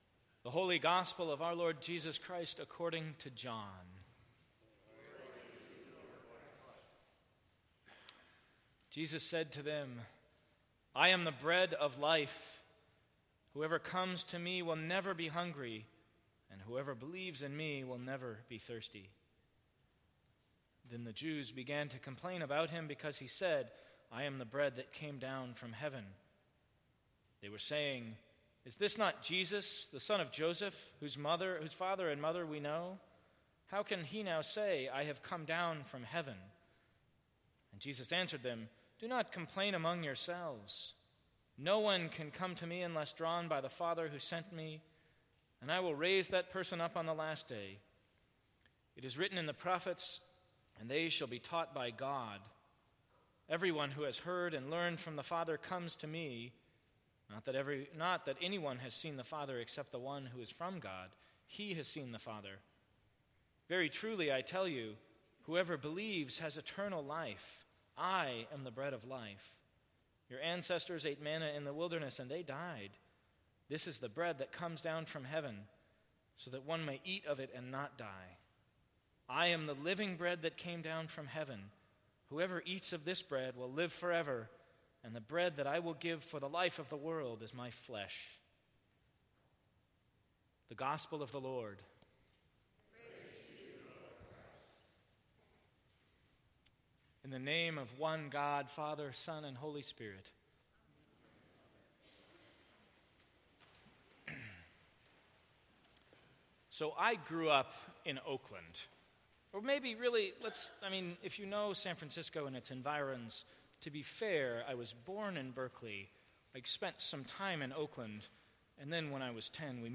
Sermons from St. Cross Episcopal Church Aren’t you hungry?